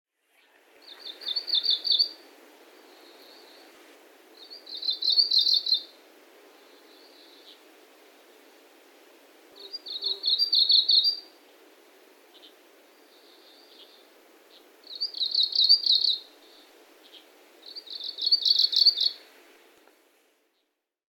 ２　メボソムシクイ【目細虫食】　全長約13cm
【録音4】 　2019年8月7日　日光白根山
「チョリチョリ、チョリチョリ、チョリチョリ」と４音ではっきりと鳴く。